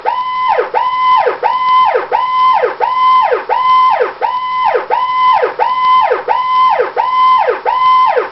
Siren- wah... wah